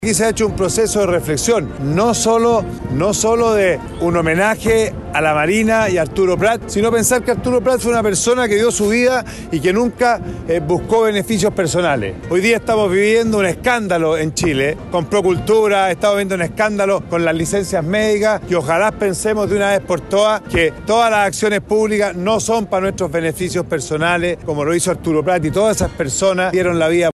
Con una multitud reunida en la Plaza Sotomayor de Valparaíso, se desarrolló este miércoles el tradicional desfile en conmemoración del 21 de mayo, fecha en que se recuerda el Combate Naval de Iquique y las Glorias Navales.
El presidente del Senado, Manuel José Ossandón, valoró el carácter republicano y simbólico del acto.
cu-ossandon-desfile.mp3